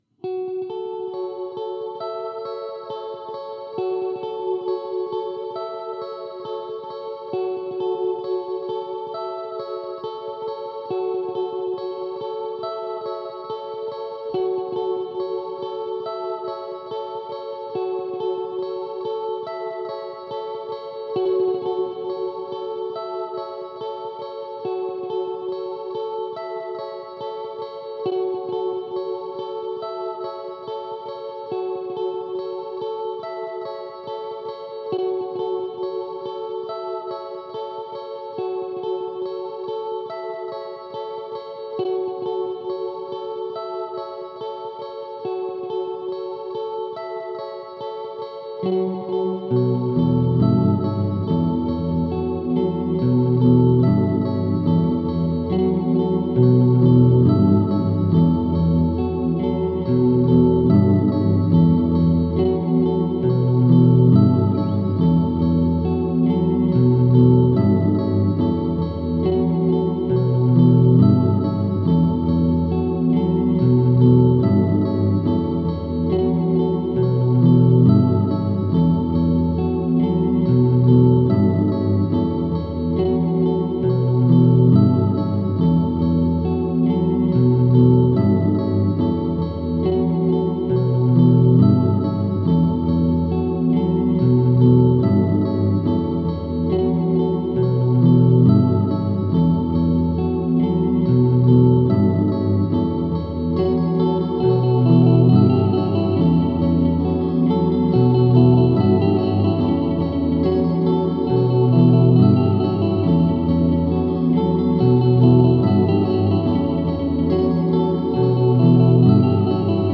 ambient guitar music
ambient music See all items with this value